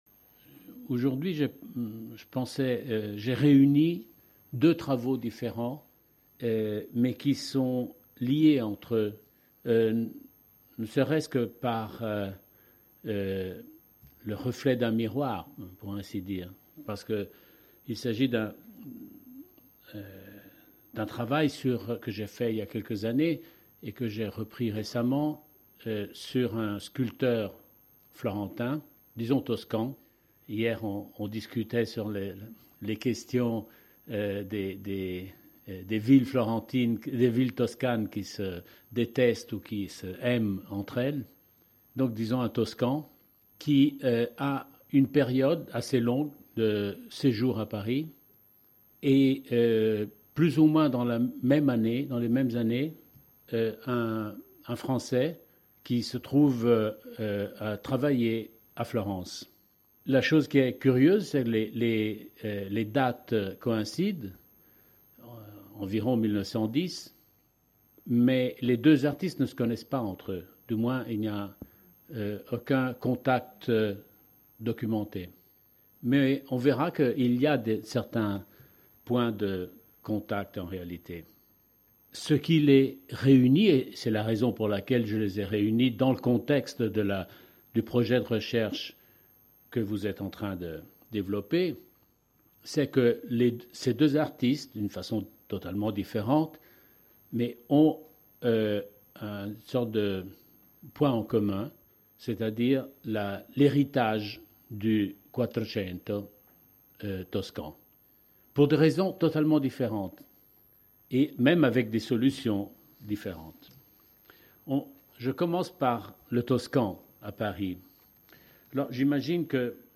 La double conférence intitulée « Artistes en regard » est consacrée à deux artistes peu connus du début XXe siècle : un sculpteur toscan à Paris, Libero Andreotti (1875-1933), et un décorateur de théâtre, peintre fresquiste parisien en Toscane, René Piot (1866-1934). Le premier étant interprété comme une sorte de Donatello en France, le second ayant proposé une curieuse interprétation de la Renaissance florentine.